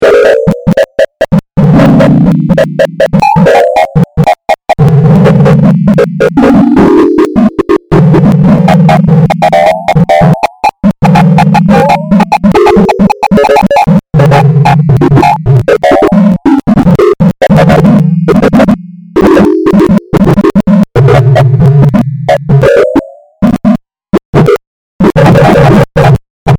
最後は無理矢理終わらせた。